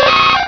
-Replaced the Gen. 1 to 3 cries with BW2 rips.
nidoran_m.aif